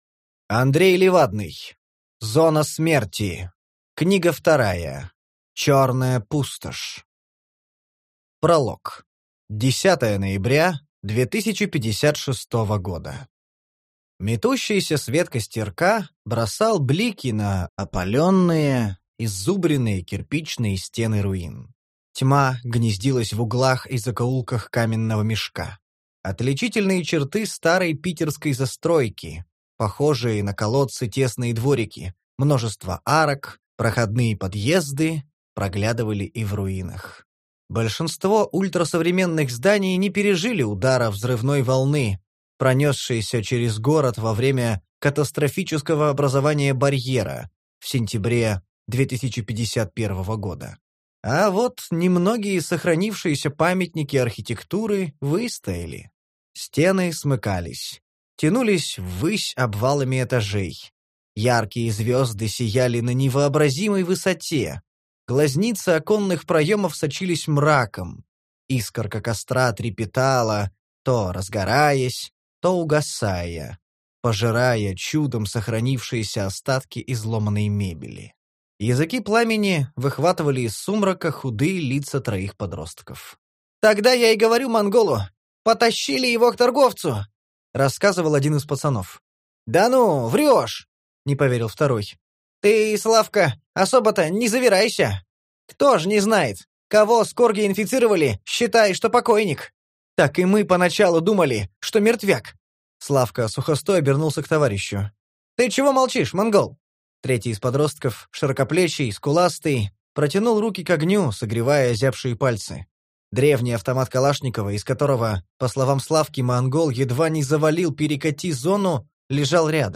Аудиокнига Черная пустошь | Библиотека аудиокниг